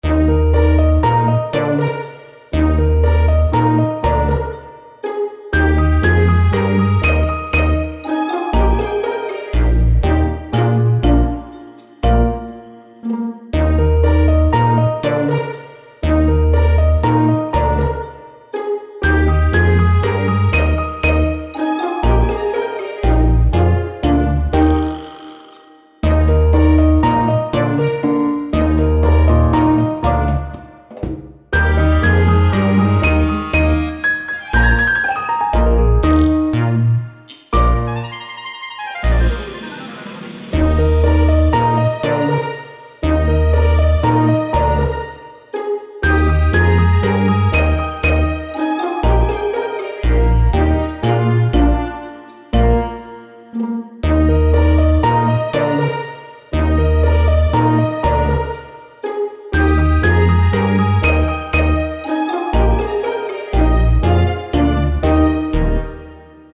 オーケストラサウンドと、ピアノサウンドのマッタリ・コミカル系の楽曲です。